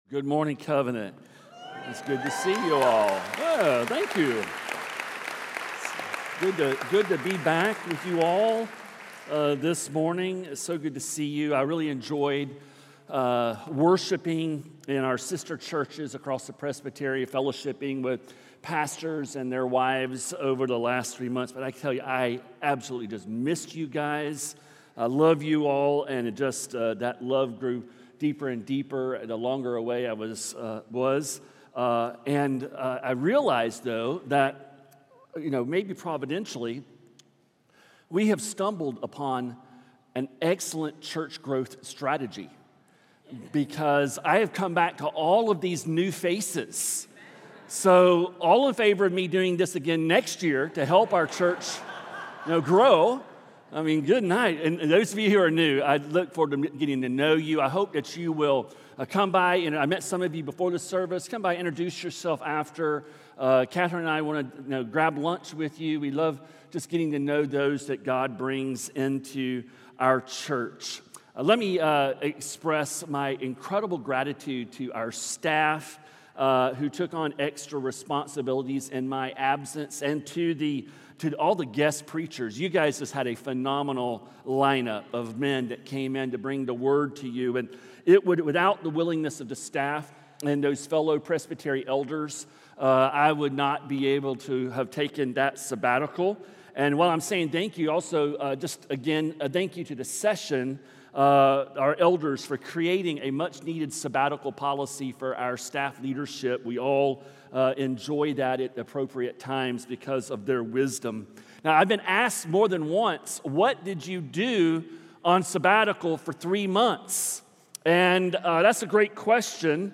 A message from the series "Holy God Holy People."